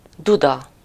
Ääntäminen
UK : IPA : /pʌɪp/ US : IPA : /paɪp/